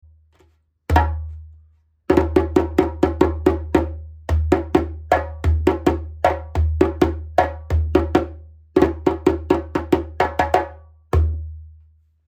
ボディ ギニア 打面 30.５cm、高さ6２cm、重量6.7kg 木材 不明 ヤギ皮 マリ（やや厚め） 縦ロープ：イエロー＆オリーヴ 5mm made in China クレードルロープ：イエロー＆グレー 4mm made in China
持ち運びも楽チンな重さ6キロ、厚めの皮を張ってるので特に中音が太く、無骨な伴奏も可能です。